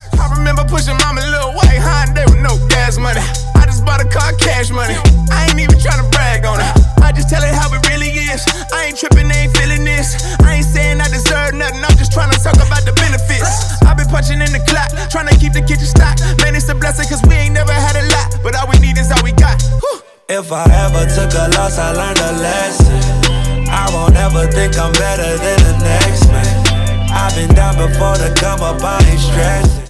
• Hip-Hop/Rap